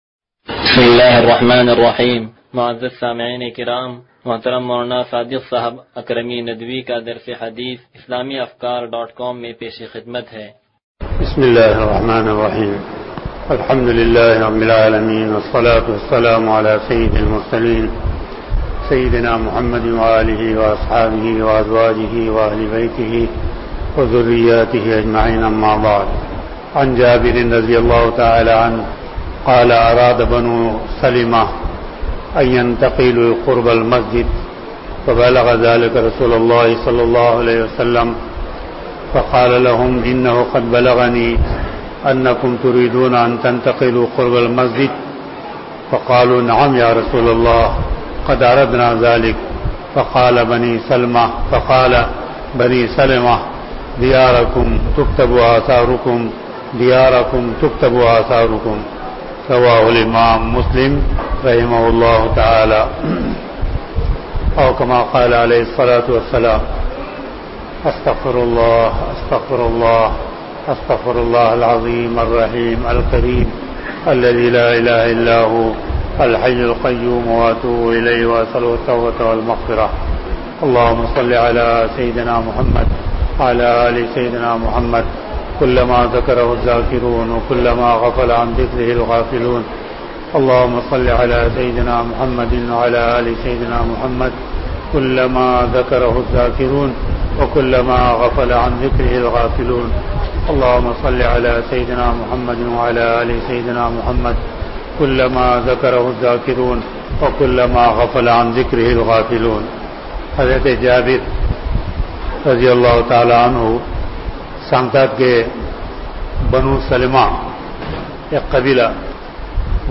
درس حدیث نمبر 0142